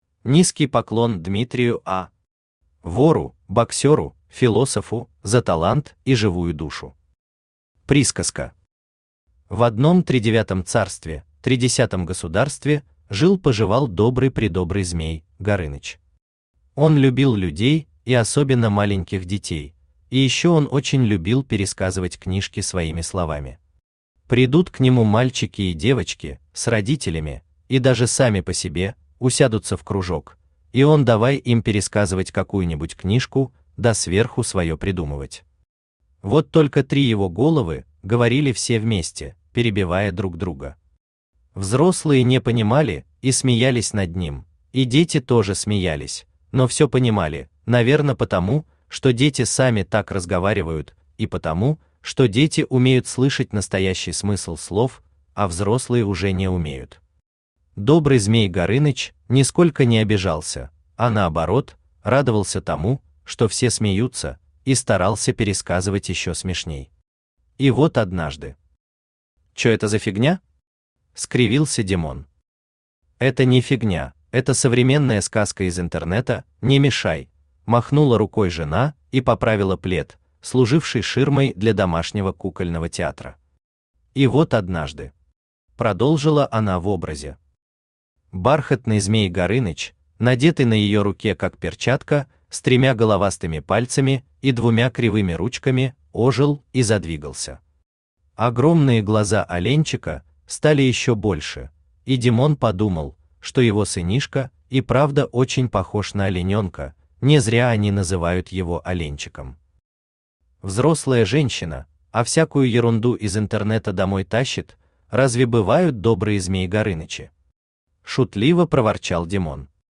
Aудиокнига Были для пацана Автор 100 Рожева Читает аудиокнигу Авточтец ЛитРес.